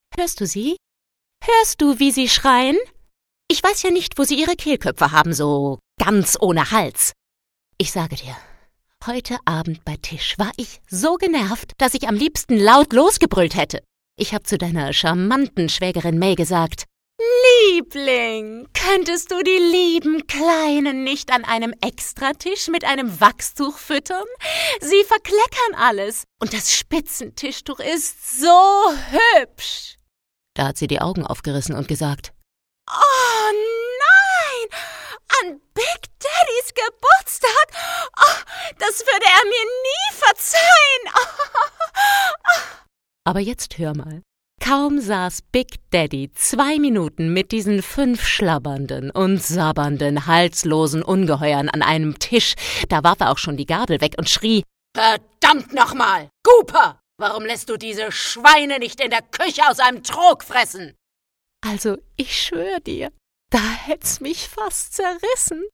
facettenreich,dynamisch, kräftig, schrill, kindlich, lieblich, verführerisch, warm, Schauspielerin, Sängerin
Sprechprobe: Industrie (Muttersprache):